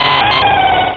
Cri de Galegon dans Pokémon Rubis et Saphir.